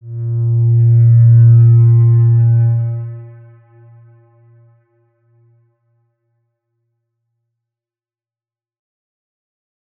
X_Windwistle-A#1-mf.wav